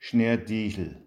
hochdeutsch Gehlbergersch
Schneetiegel  Schneadiechl